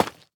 Minecraft Version Minecraft Version 1.21.5 Latest Release | Latest Snapshot 1.21.5 / assets / minecraft / sounds / block / tuff_bricks / place2.ogg Compare With Compare With Latest Release | Latest Snapshot